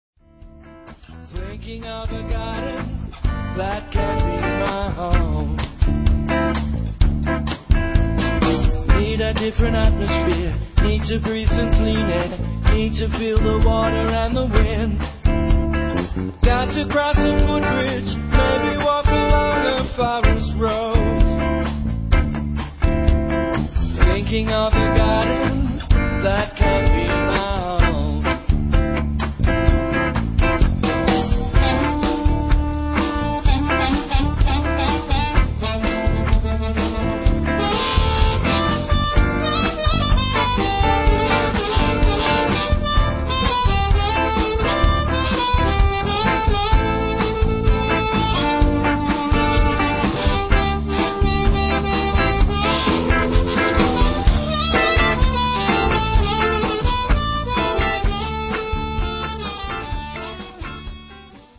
A live anthology of my work in bands from 1971 through 2001.